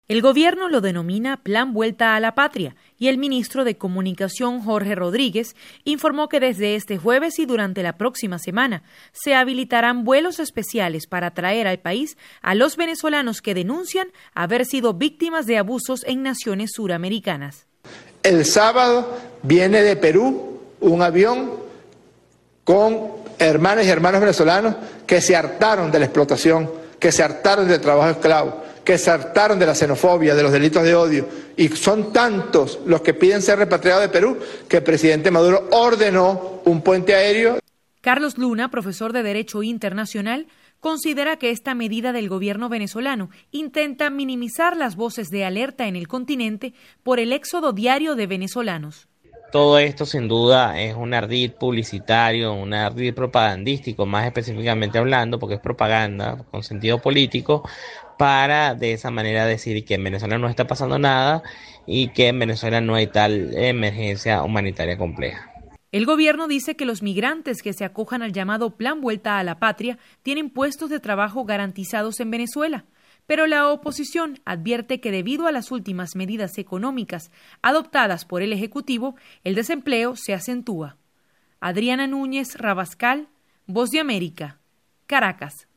VOA: Informe de Venezuela